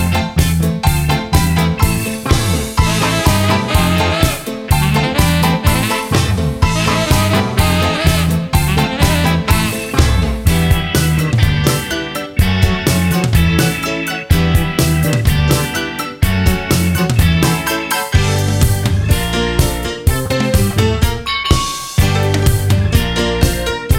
no Backing Vocals Ska 2:50 Buy £1.50